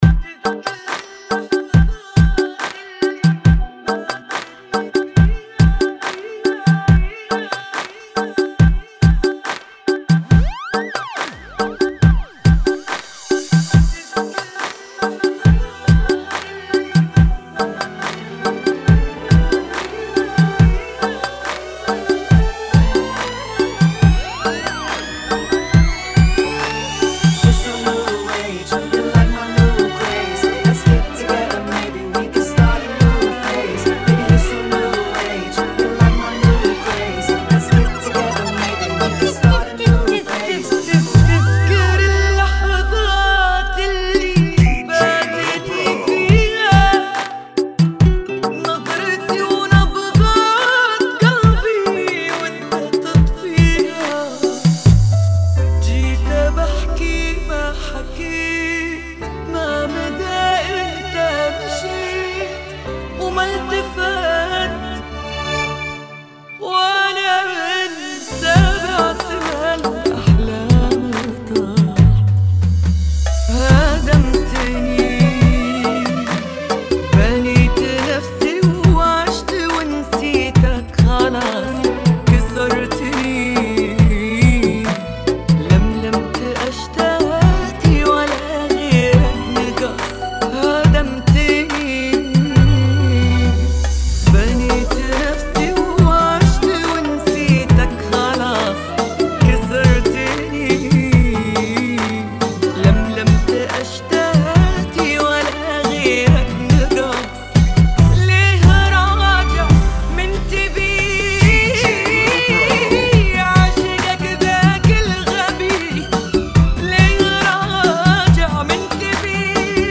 70 Bpm ] - Funky